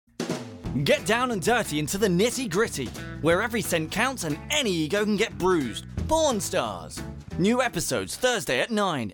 Male
Professional recording studio, Rode NT1A, Reaper, Izotope RX & more.
Radio / TV Imaging
Fun And Engaging